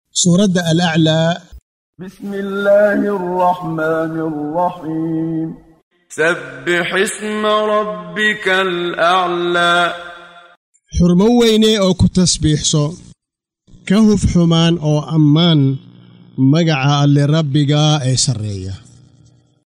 Waa Akhrin Codeed Af Soomaali ah ee Macaanida Suuradda Al-Aclaa ( Sarreeye Ma Nuqsaame ) oo u kala Qaybsan Aayado ahaan ayna la Socoto Akhrinta Qaariga Sheekh Muxammad Siddiiq Al-Manshaawi.